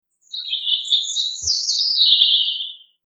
Bird Chirping
Bird_chirping.mp3